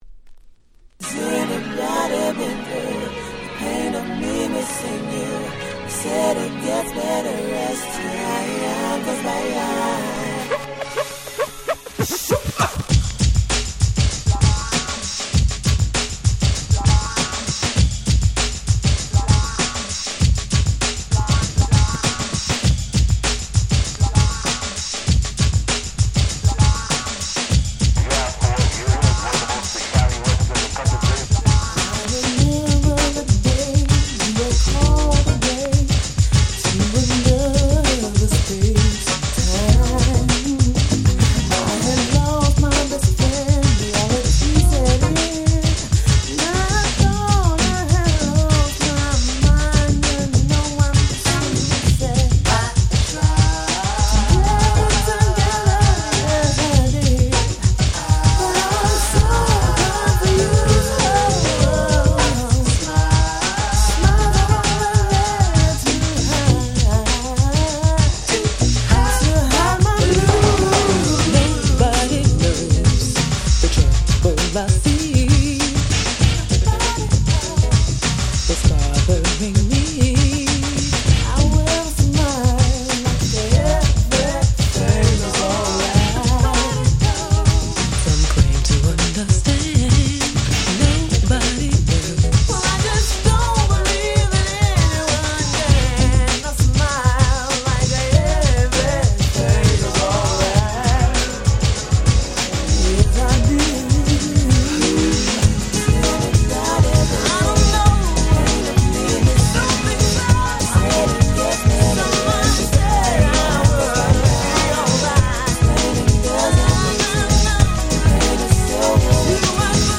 91' Nice UK Street Soul !!
マイナーUKストリートソウル！！
「これぞUK Street Soul !!」と言った感じの非常にいなたい1曲。
R&B